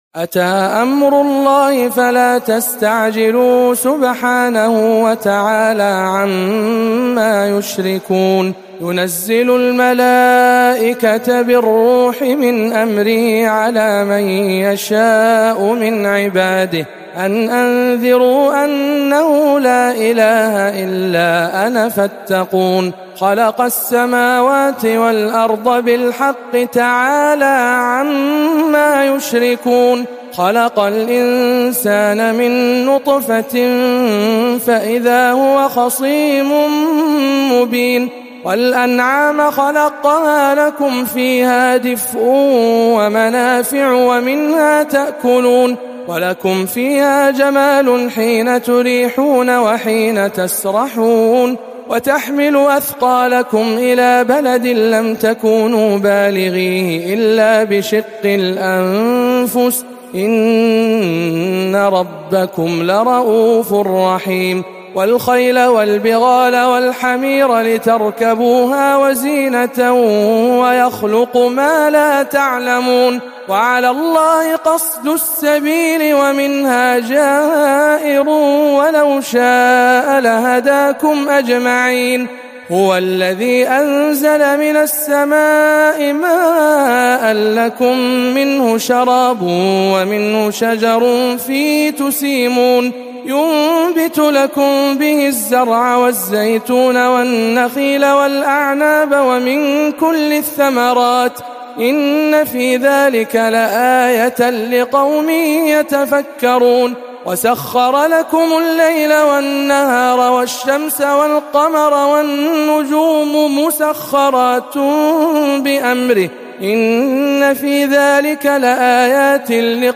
سورة النحل بجامع أم الخير بجدة